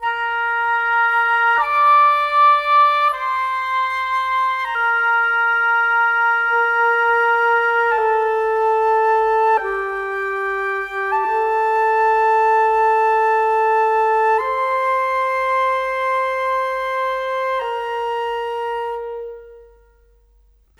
Rock-Pop 17 Winds 01.wav